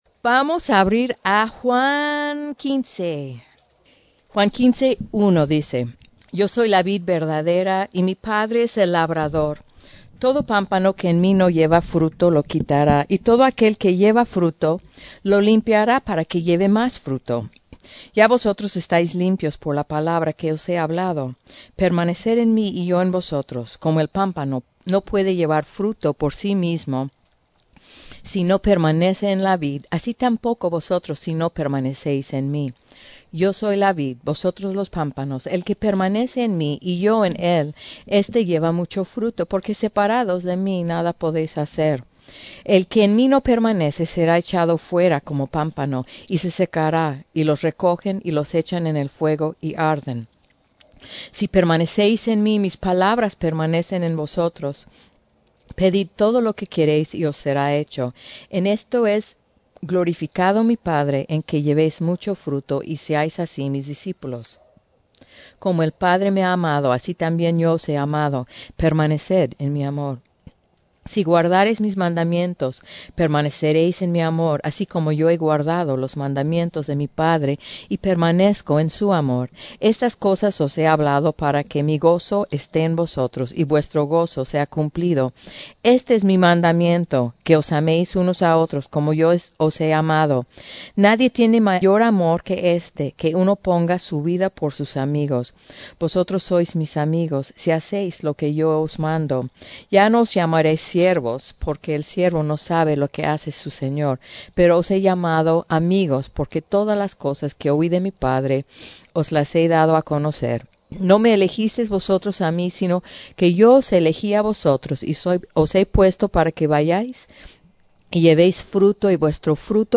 Retiro Staff 2018